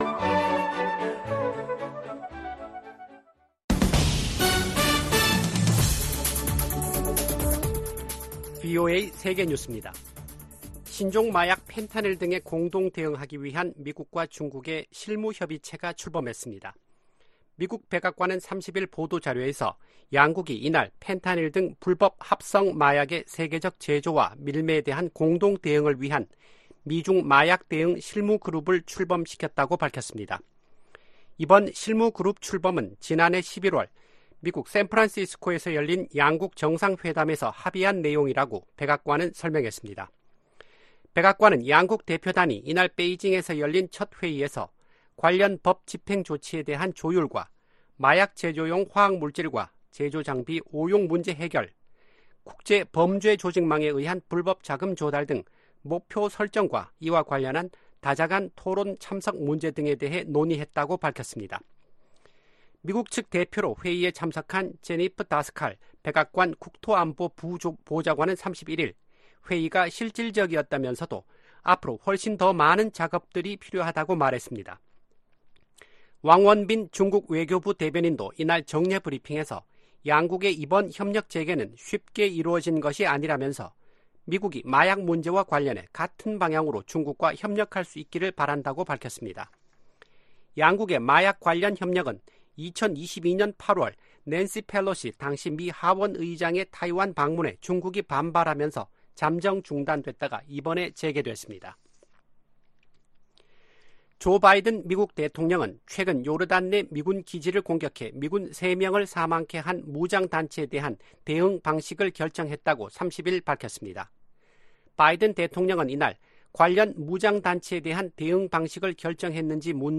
VOA 한국어 아침 뉴스 프로그램 '워싱턴 뉴스 광장' 2024년 2월 1일 방송입니다. 윤석열 한국 대통령은 북한이 총선을 겨냥한 도발을 벌일 것이라며 총력 대비해야 한다고 강조했습니다. 미 공화당 하원의원들이 자동차업체 포드 사와 계약을 맺은 중국 업체들이 북한 정부 등과 연계돼 있다고 밝혔습니다.